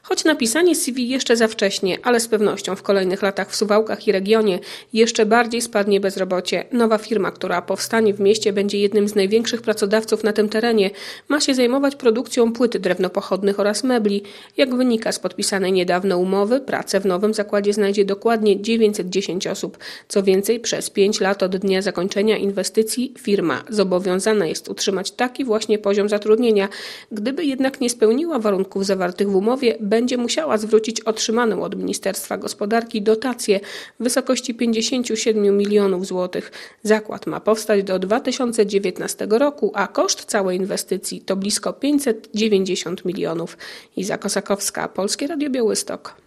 W Suwałkach powstaną nowe bloki - relacja